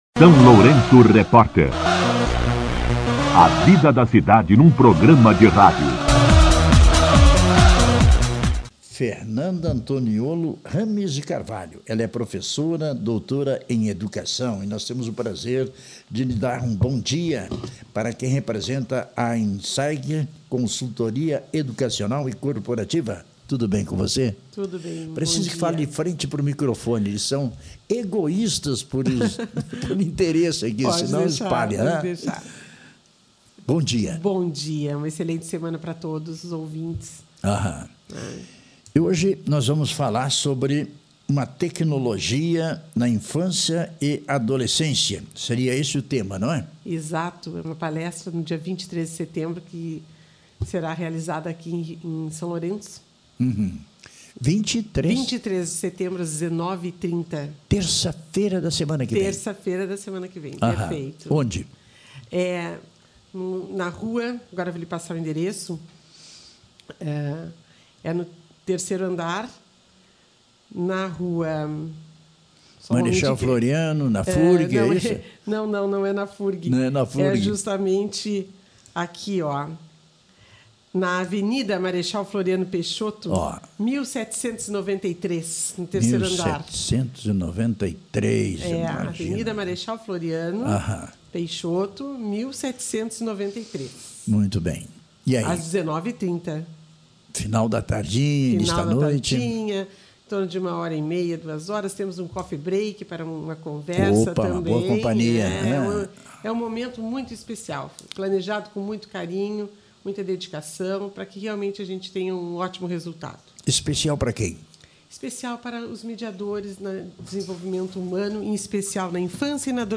Na manhã desta segunda-feira (15), a profissional concedeu entrevista ao SLR RÁDIO, destacando a importância do tema. O objetivo do encontro é oferecer subsídios teóricos, baseados nas mais recentes descobertas da neurociência, sobre os impactos positivos e negativos das tecnologias digitais no desenvolvimento integral de crianças e adolescentes.